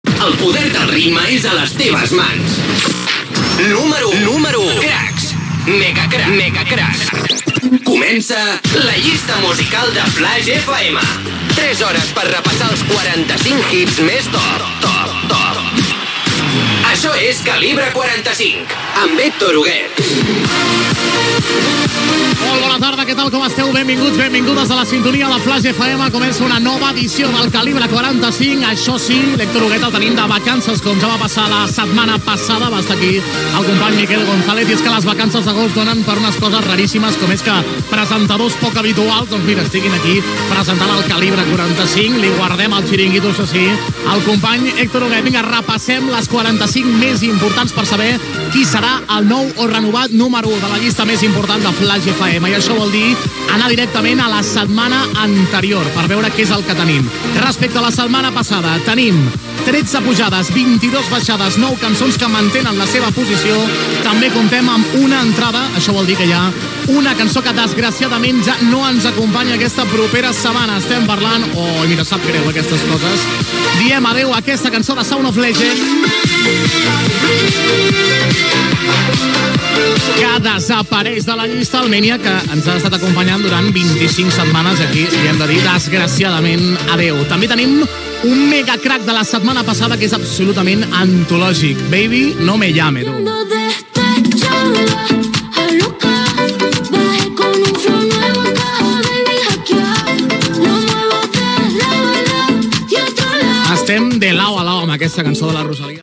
Indicatiu del programa, estat de la llista d'èxits de l'emissora i entrada del tema "Despechá" de Rosalia.
Musical